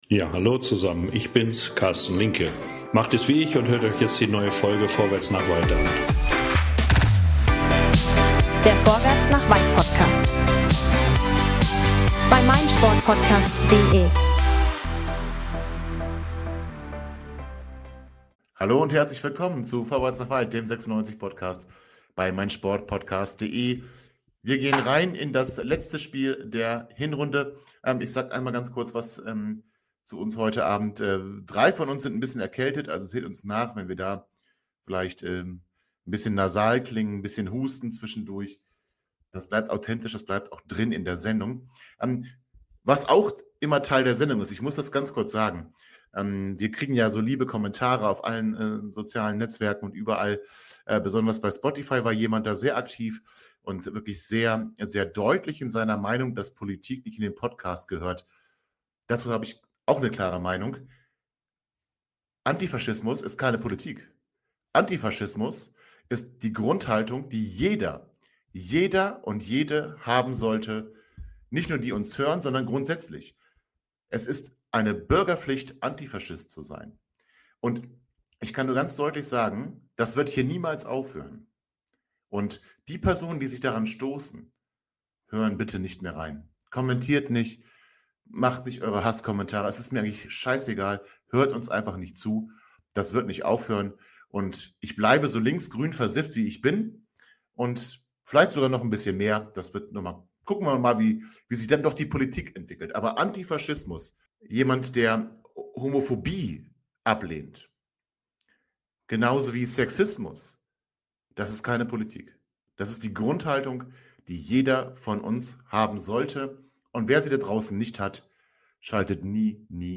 So quälen sich drei Viertel heute für euch durch die Sendung und erklären euch, weshalb es am Sonntag nochmal einen schönen Auswärtssieg zu feiern geben wird.
Am Ende wünsche wir euch ein frohes Weihnachtsfest und einen guten Rutsch ins neue Jahr - heute aber leider ohne Gesang.